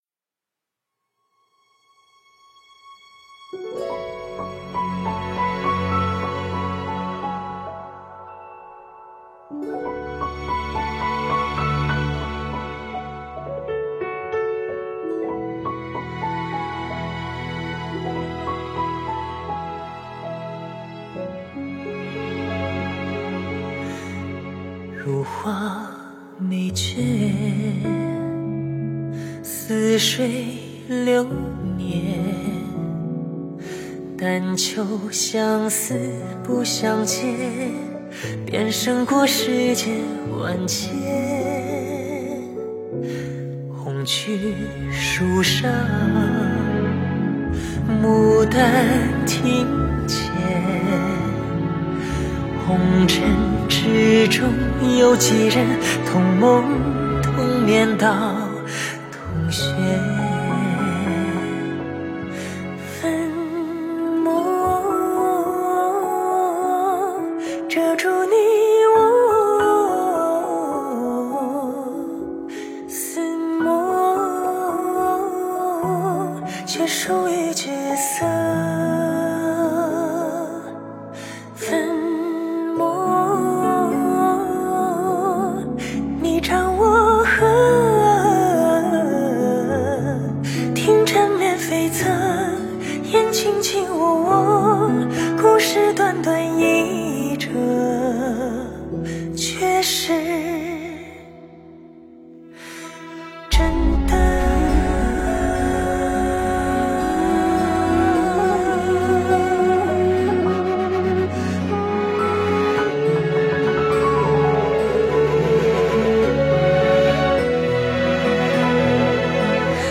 佛音 诵经 佛教音乐 返回列表 上一篇： 不送帖 下一篇： 卷珠帘 相关文章 暮钟偈--齐豫 暮钟偈--齐豫...